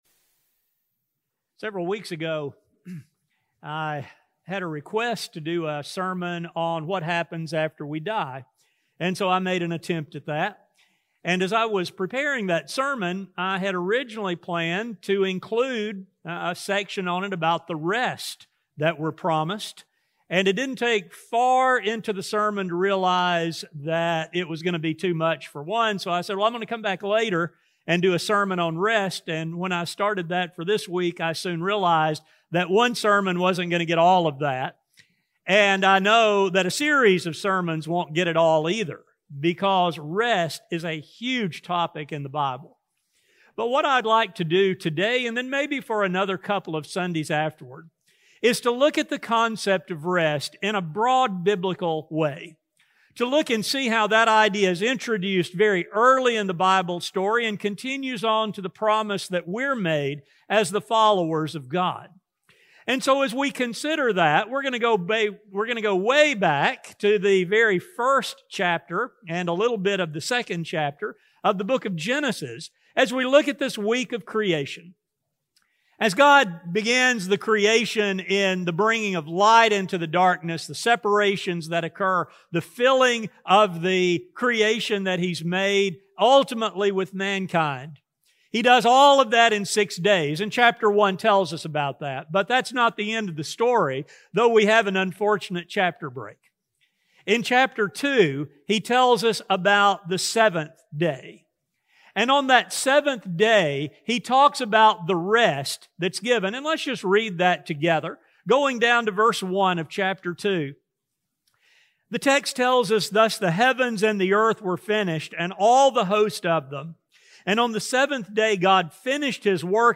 This lesson begins a short series of sermons on this topic, entitled, “Rest, in Peace.” These studies will focus on episodes from ancient Israel that are ultimately used by the writer of Hebrews as he explains the promised rest that God offers.